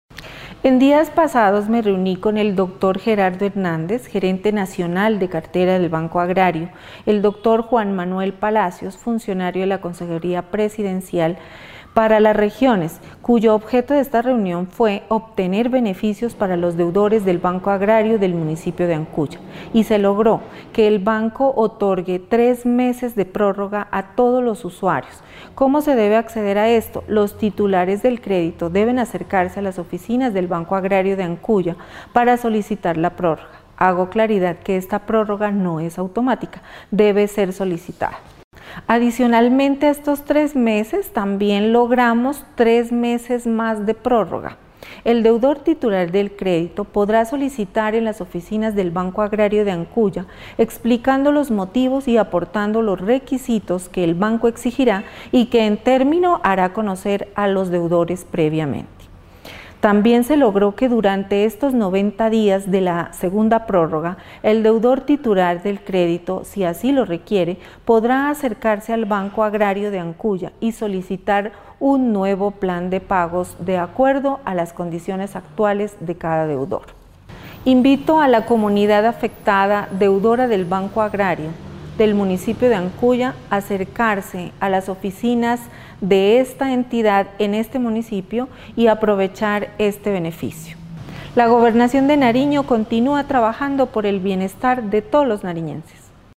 Declaración de la secretaria de hacienda Viviana Solarte: